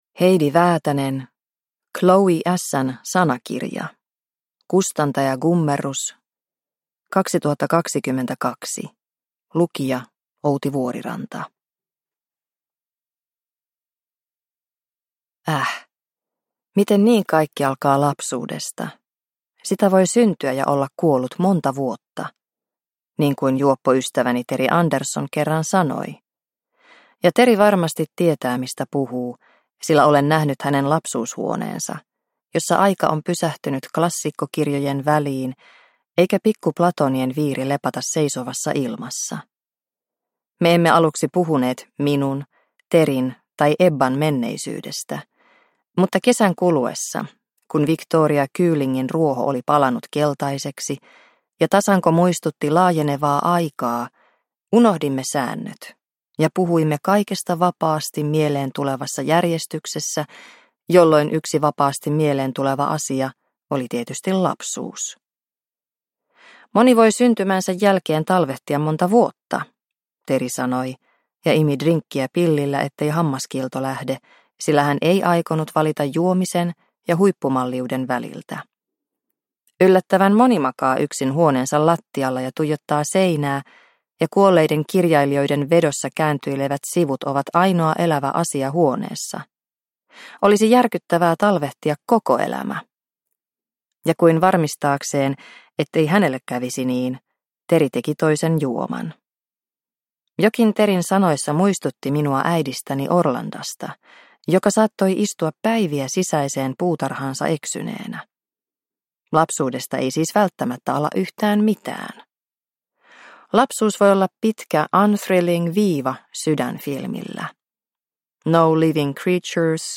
Chloé S – Ljudbok – Laddas ner